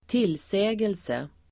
Uttal: [²t'il:sä:gelse el. -sej:else]